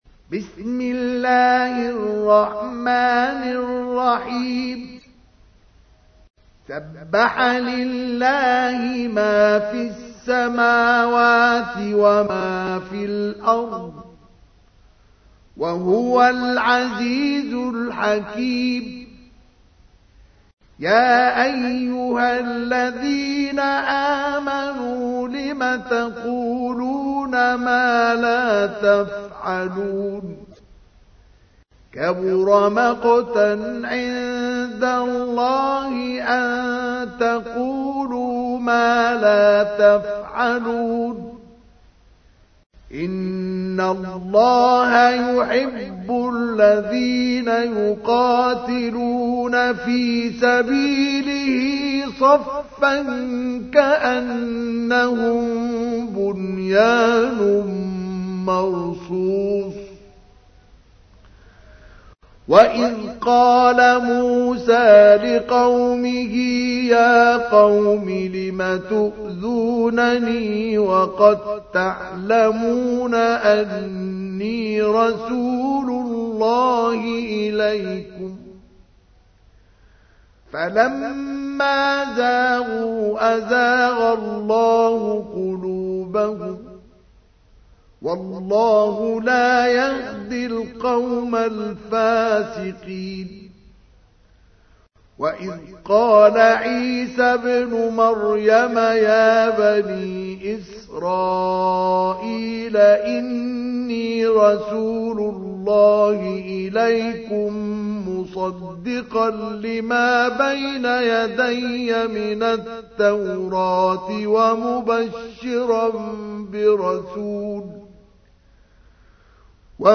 تحميل : 61. سورة الصف / القارئ مصطفى اسماعيل / القرآن الكريم / موقع يا حسين